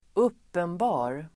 Uttal: [²'up:enba:r]